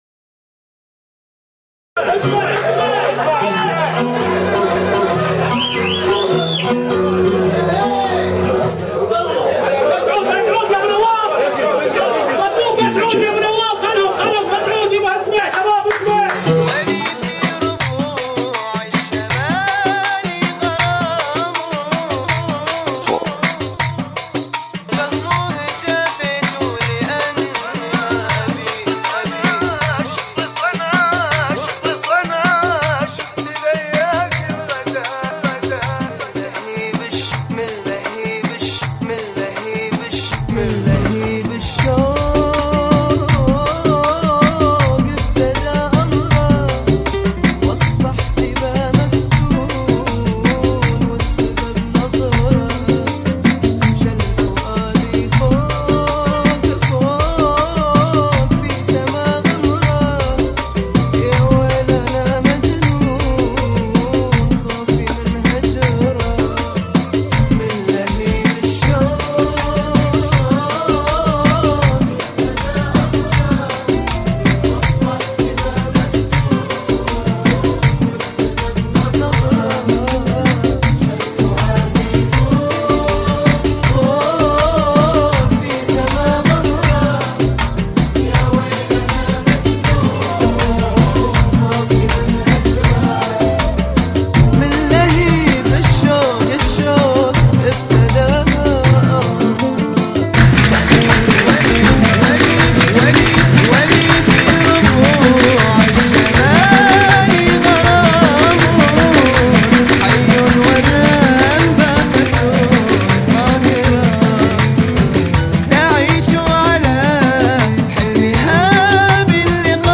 MiniMix